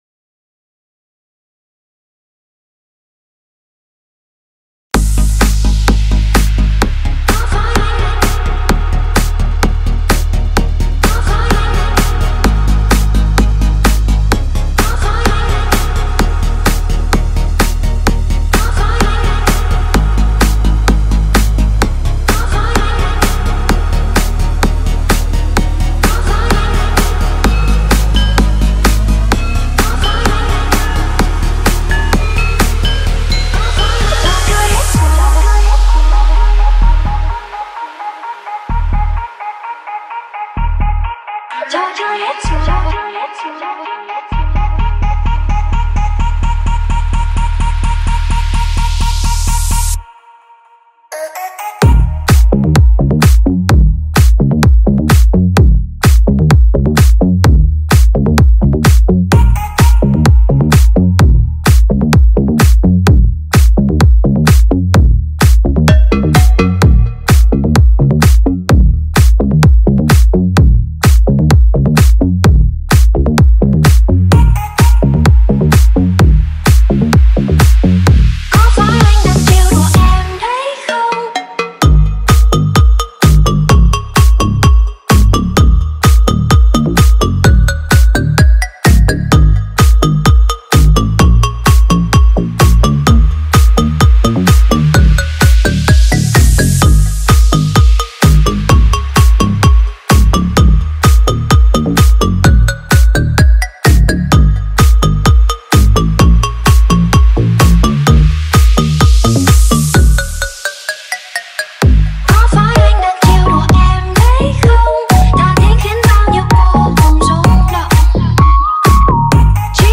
(Remix)